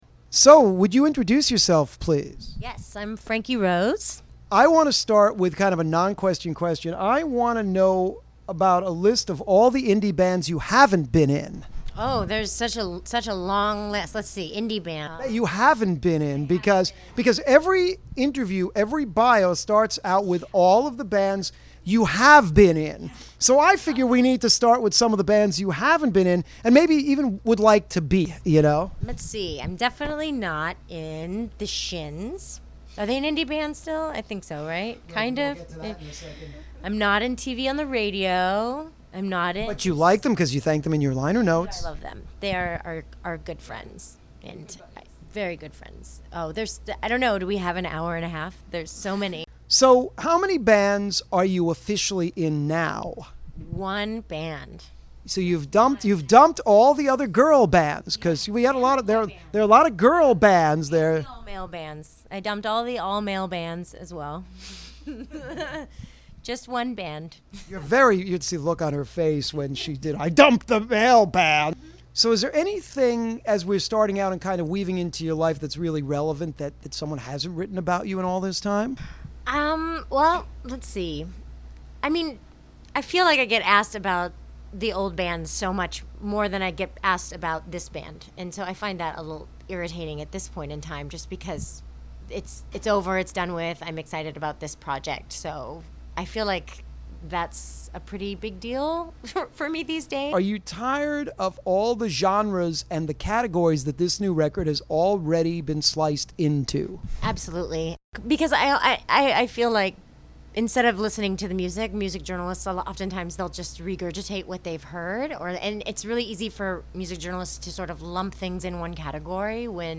Also, be sure to listen for our exclusive “Musical Sandwich” featuring two of the interviewed artist’s songs as the bread and as many musical fillings as they can muster up in-between!